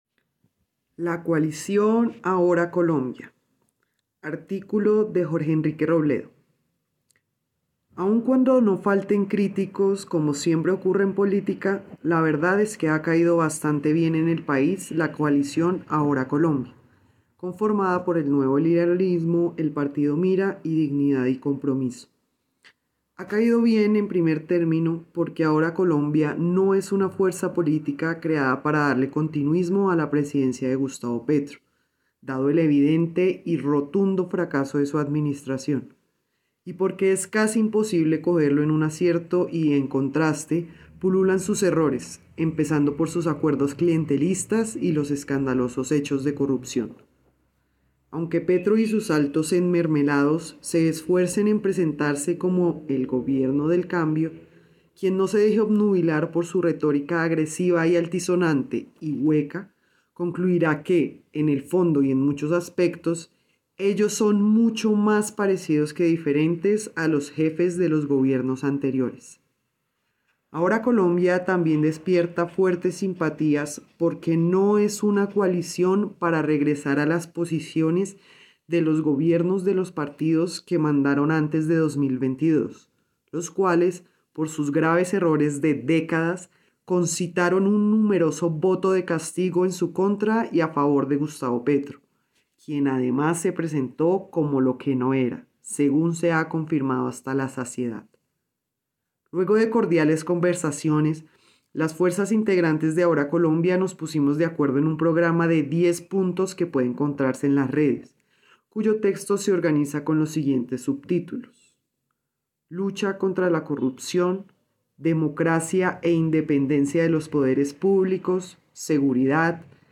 Lectura: